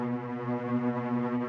buildup_loop1.wav